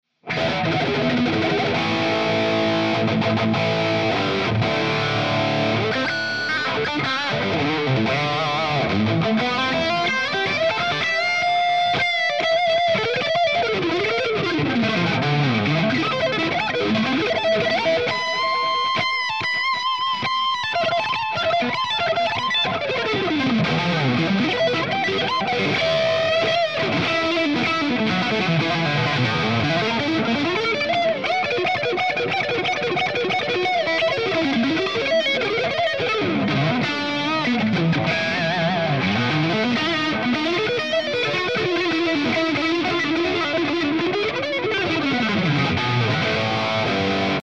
Very simple to get great tones, no switching or options just raw jaw dropping distortion tone.
Improv
RAW AUDIO CLIPS ONLY, NO POST-PROCESSING EFFECTS
Hi-Gain